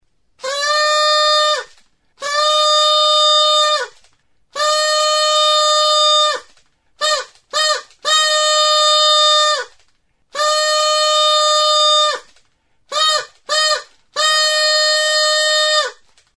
MATASUEGRAS; Aire serpentina | Soinuenea Herri Musikaren Txokoa
Enregistré avec cet instrument de musique.
Instruments de musique: MATASUEGRAS; Aire serpentina Classification: Aérophones -> Anches -> Simple battante (clarinette) Emplacement: Erakusketa biltegia; taldeak Explication de l'acquisition: Erosia; Donostiako txutxeria denda batean erosia.
Putz egiterakoan paperezko suge koloreduna luzatu egiten da eta mihiak hotsa ematen du.